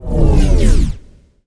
sci-fi_power_down_object_01.wav